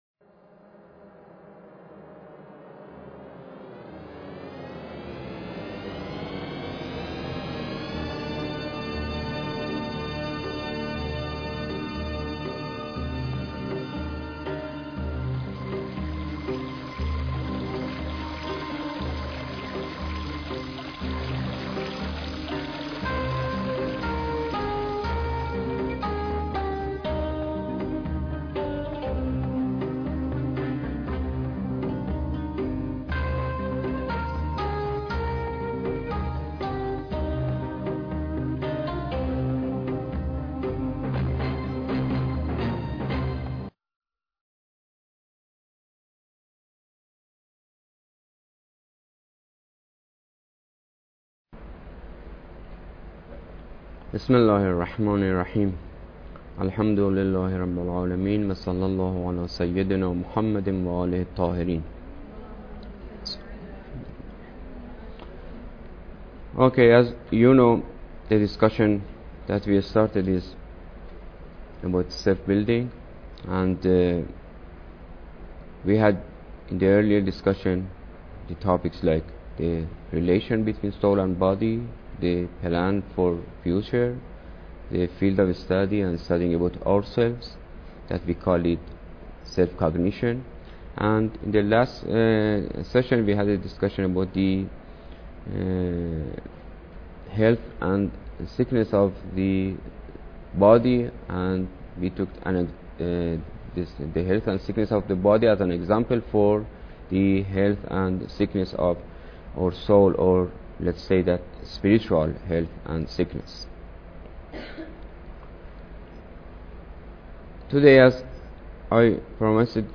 Lecture_5